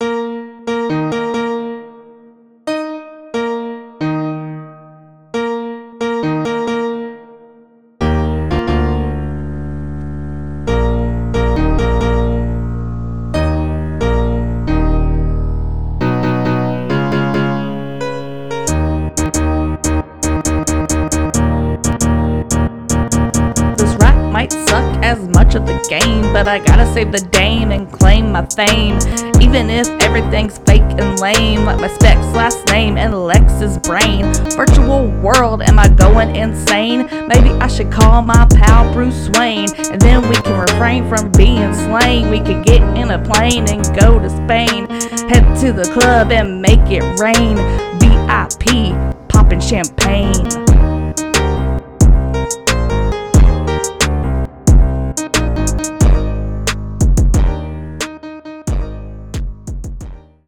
Rap from Episode 57: Superman 64 – Press any Button
Superman-64-rap.mp3